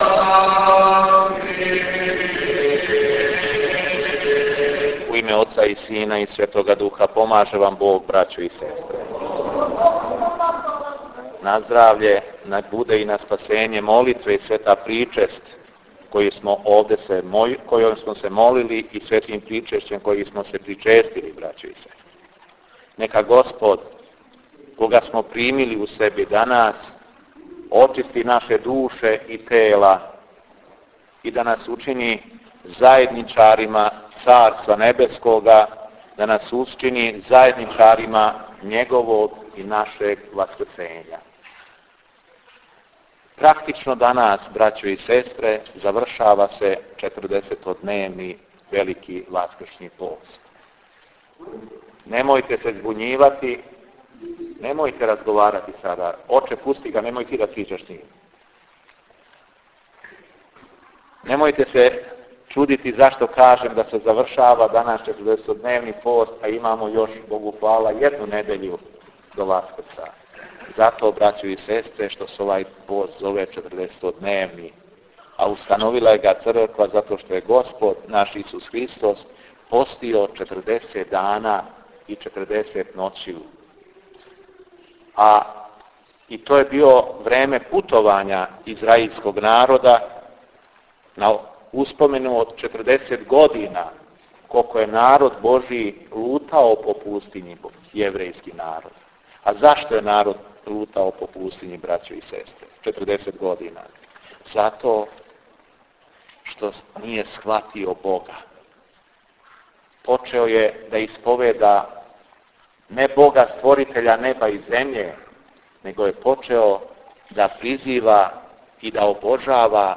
Беседа у Ковачевцу